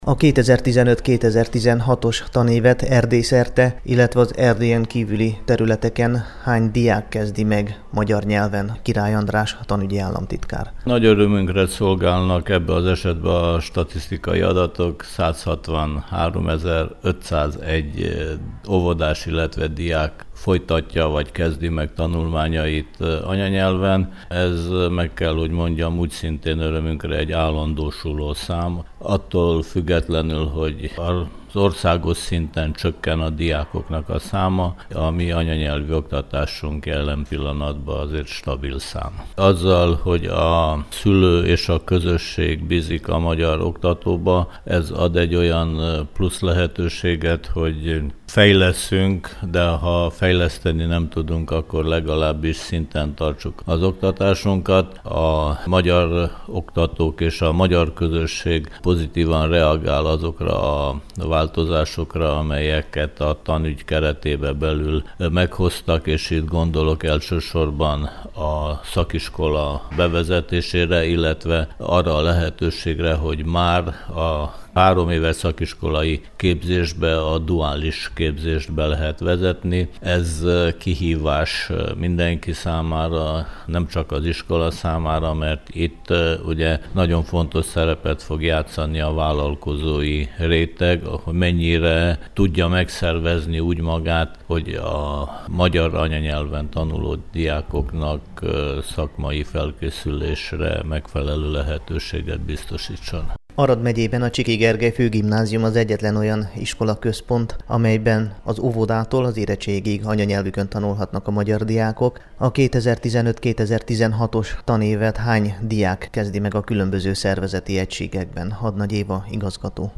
Több mint 163 ezer diák kezdte meg ma az iskolát magyarul Erdély-szerte – jelentette ki az RMDSZ oktatási államtitkára, Király András a Csiky Gergely Főgimnázium tanévnyitó ünnepségén. Az aradi magyar iskolaközpontban az óvodától a tizenkettedik osztályig 650-en gyermeknek csengett ma be.
tanevnyito_a_csikyben_2015.mp3